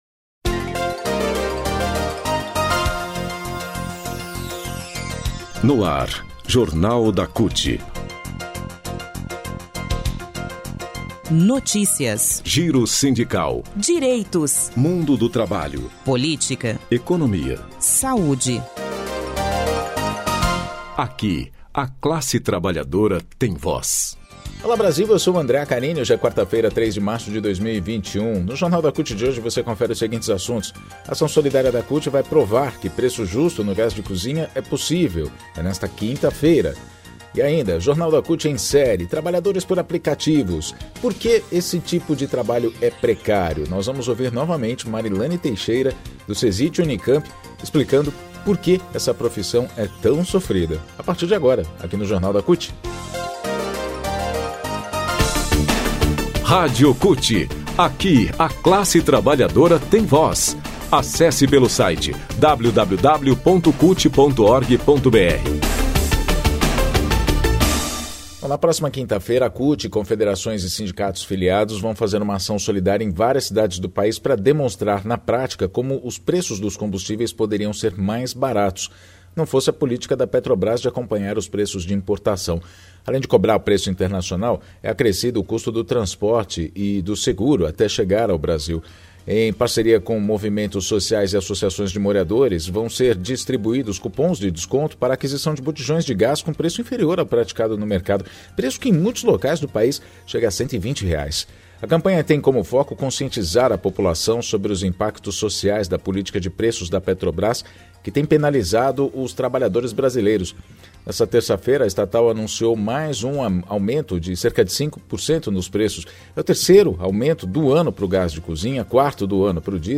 Jornal de rádio da CUT